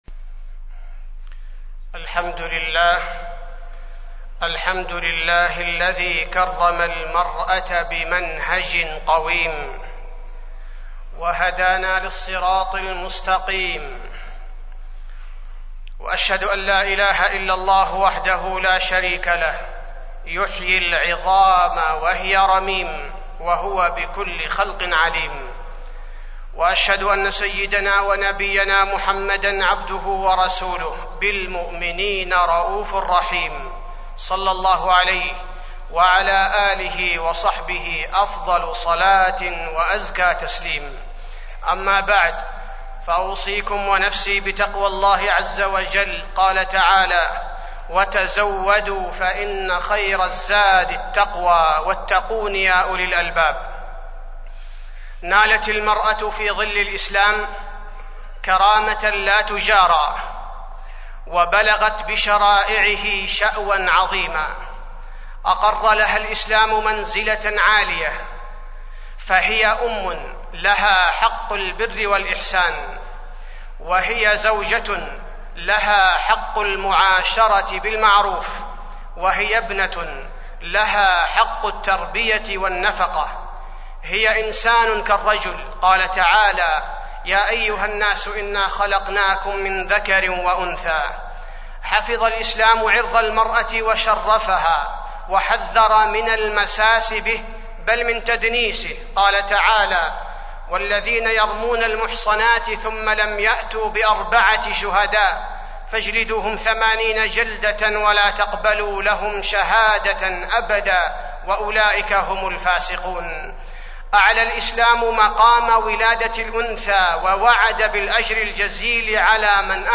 تاريخ النشر ٣ صفر ١٤٢٧ هـ المكان: المسجد النبوي الشيخ: فضيلة الشيخ عبدالباري الثبيتي فضيلة الشيخ عبدالباري الثبيتي المرأة